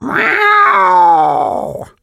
kit_hurt_vo_04.ogg